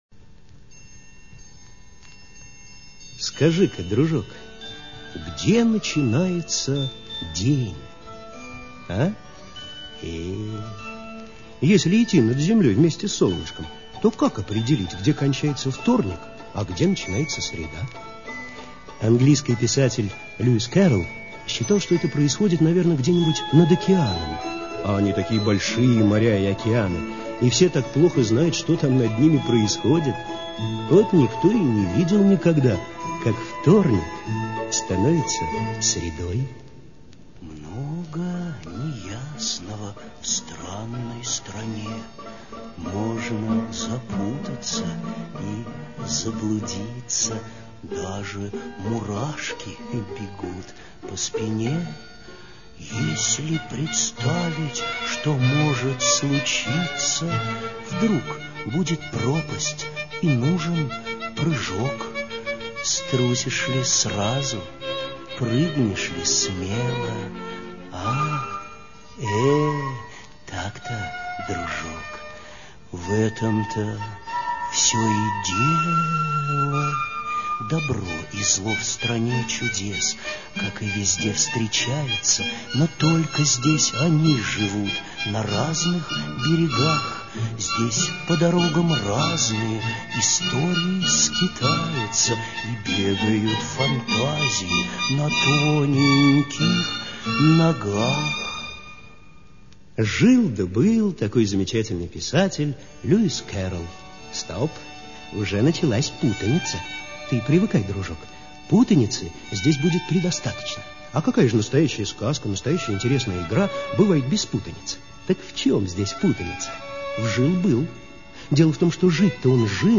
Приключения Алисы в Стране чудес - аудиосказка Кэрролл - слушать онлайн